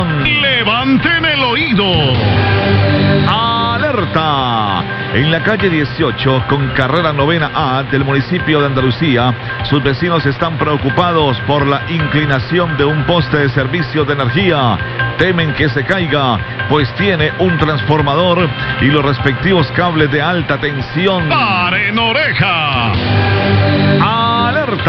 Radio
*La nota fue anunciada en los titulares pero no se desarrolló durante la emisión del resto del noticiero.